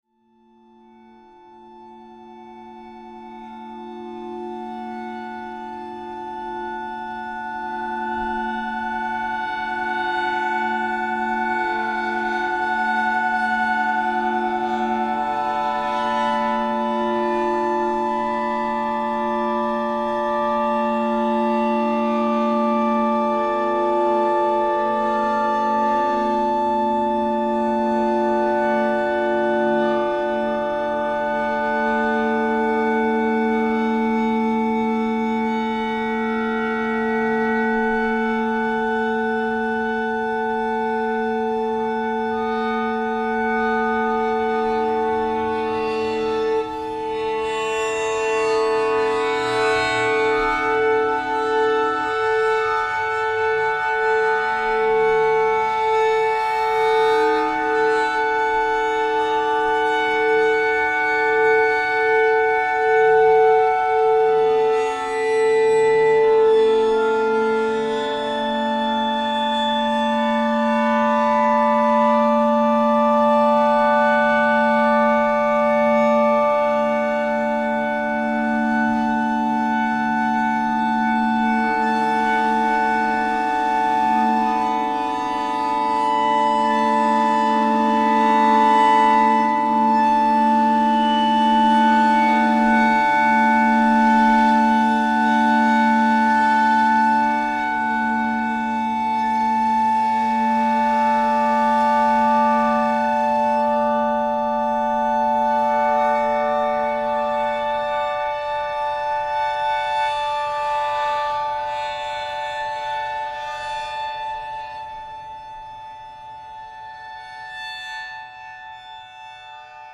experimental music
long string instrument
snare drum with cymbals